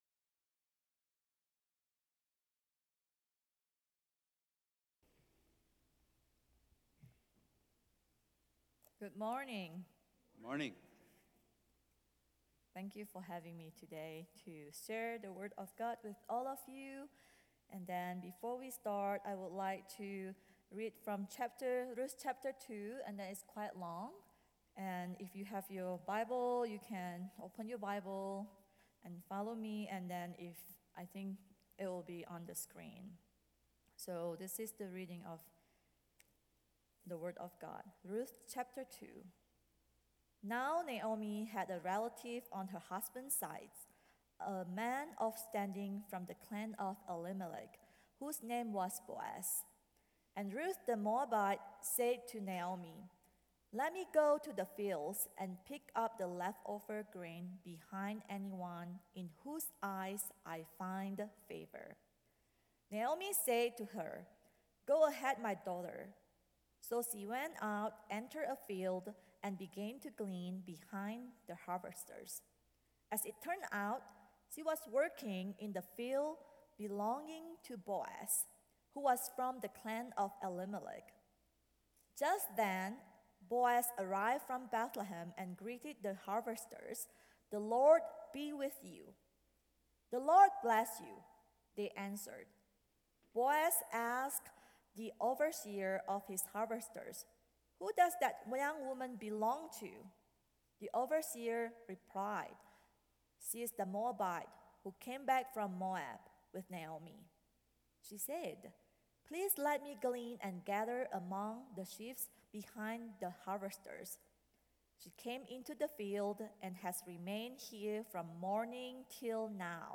A message from the series "Guest Speaker."